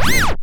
SNGL.SCREECH.wav